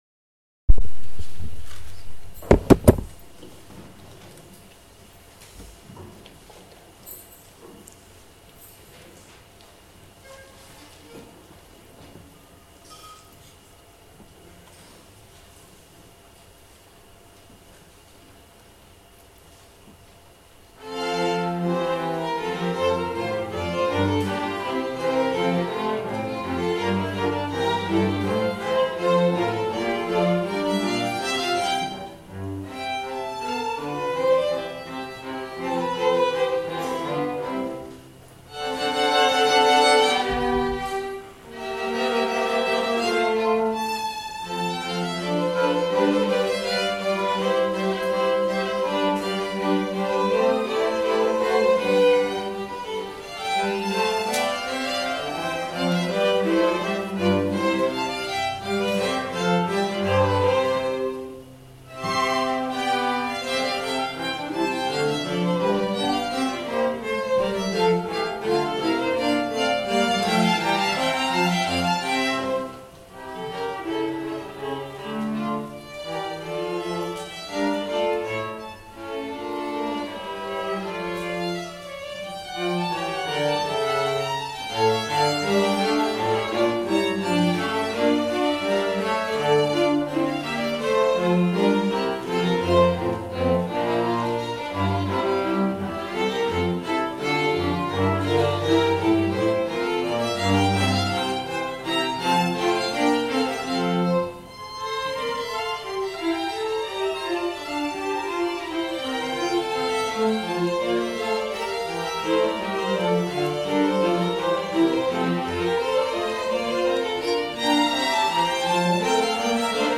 string quartet